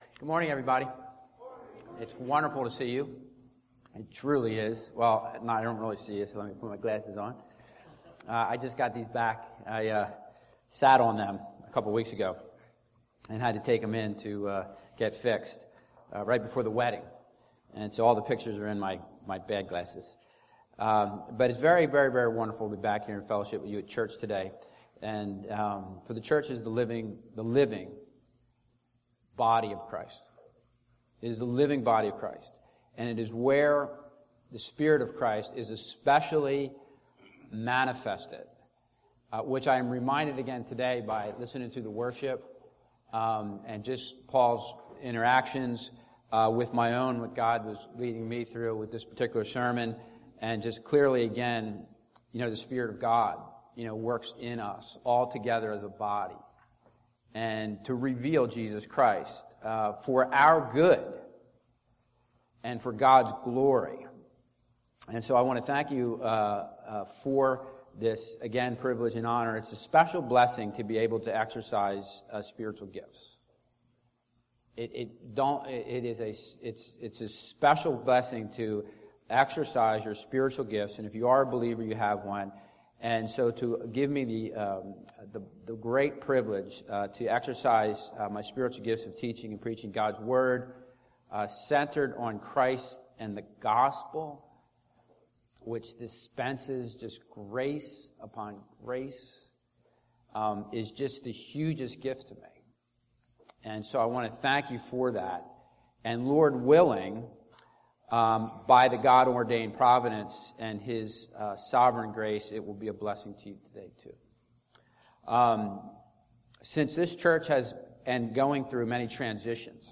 Bible Text: Galatians 1:1-5 | Preacher